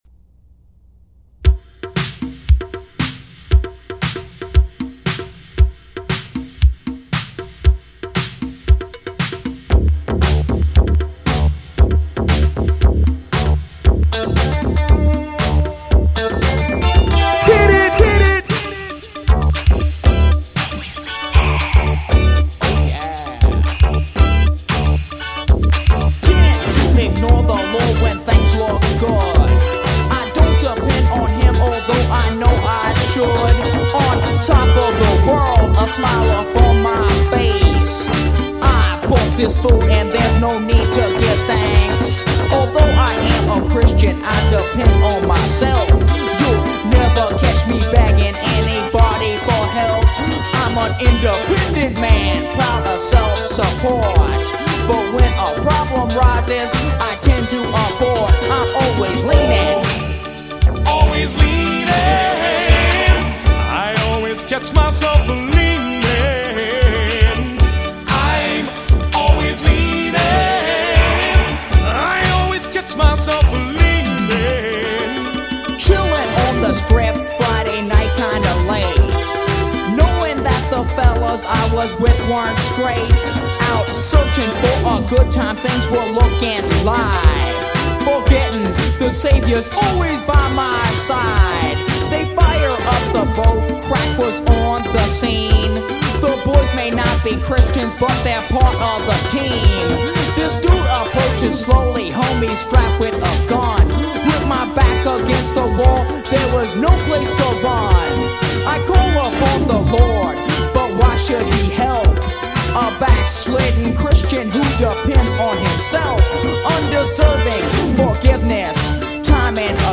demo tape